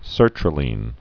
(sûrtrə-lēn)